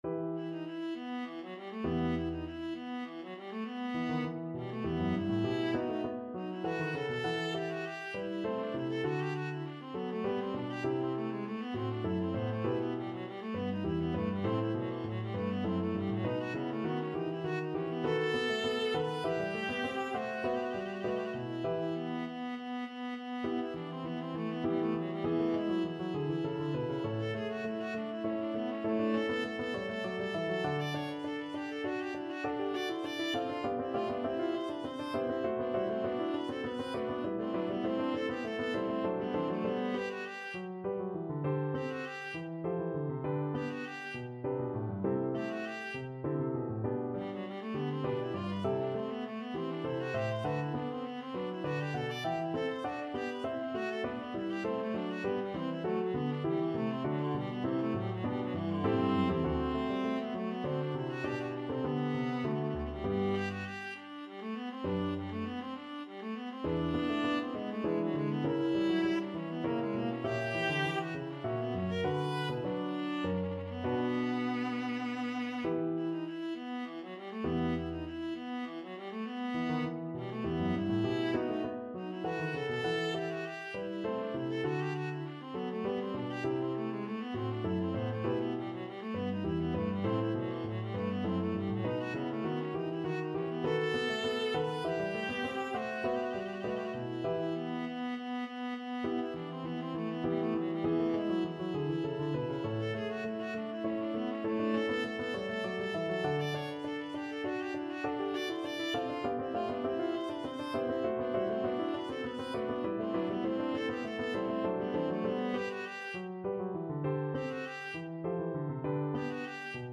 Viola version
Allegro (View more music marked Allegro)
3/4 (View more 3/4 Music)
Classical (View more Classical Viola Music)